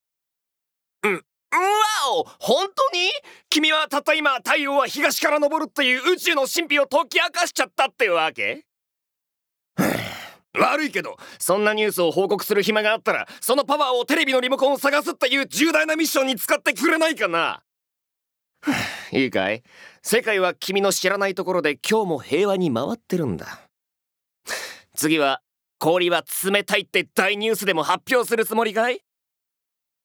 ボイスサンプル
セリフ６